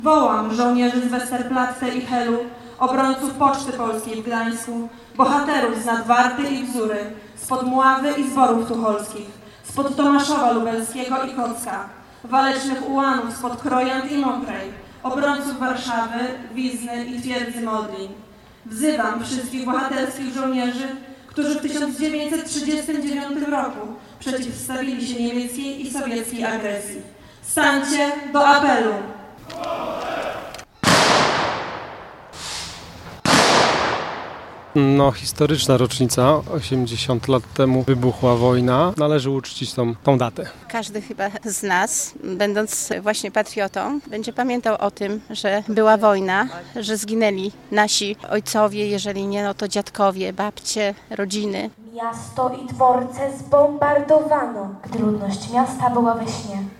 Zielonogórskie uroczystości upamiętniające wybuch II Wojny Światowej
Na placu Bohaterów o godzinie 13:00 rozpoczęły się miejskie uroczystości mające na celu upamiętnienie wybuchu II Wojny Światowej.
Na koniec wybrzmiały salwy Honorowe.